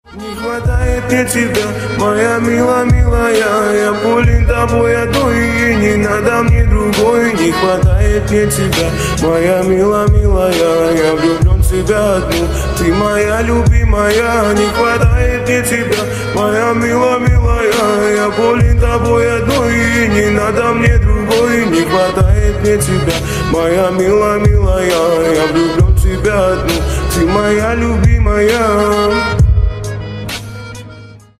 Поп Рингтоны